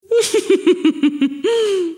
Rialles.mp3